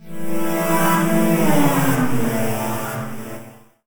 SouthSide Trap Transition (31).wav